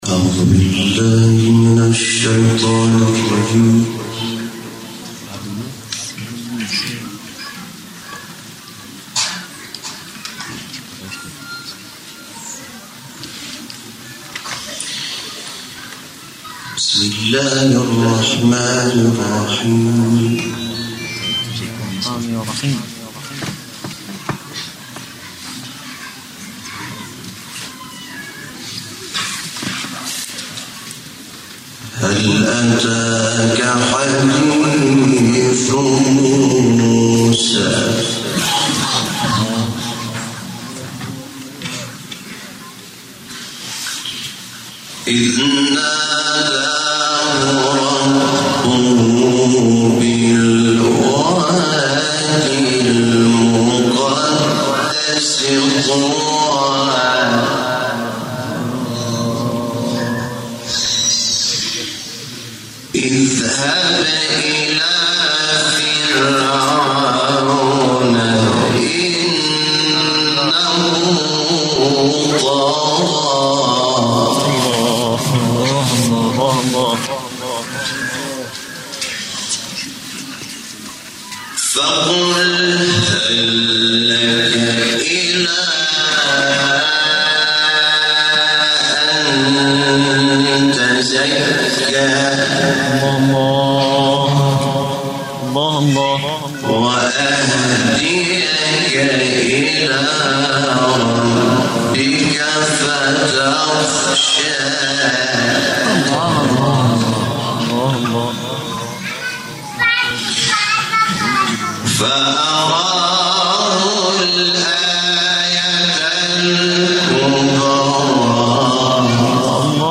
تلاوت جدید «حامد شاکرنژاد» در یاسوج
گروه شبکه اجتماعی: حامد شاکرنژاد در آیین تجلیل از برگزیدگان بیست و دومین جشنواره قرآن و عترت به تلاوت آیاتی از سوره مبارکه نازعات پرداخت.